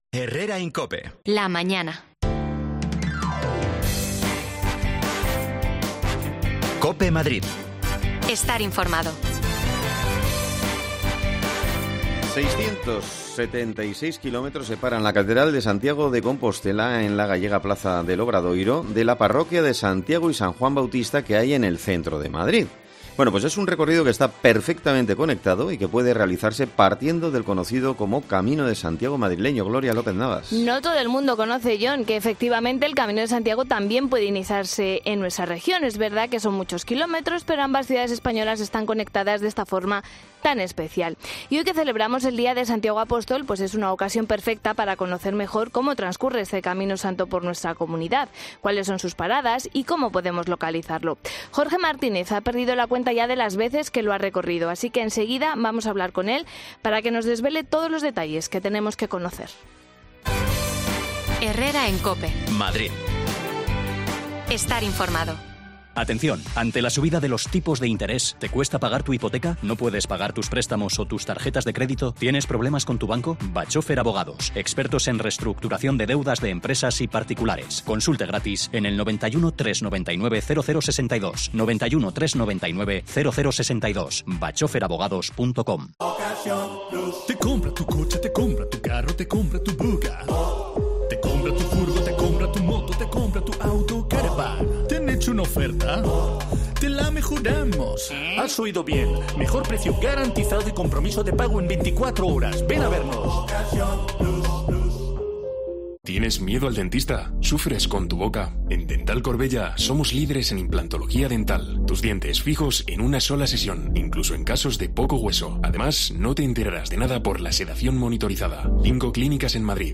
Hoy, día de Santiago, analizamos la ruta madrileña del camino que nos llevan a la capital gallega. Un experimentado peregrino nos cuenta sobre ello
Las desconexiones locales de Madrid son espacios de 10 minutos de duración que se emiten en COPE, de lunes a viernes.